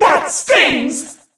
bea_mon_hurt_vo_05.ogg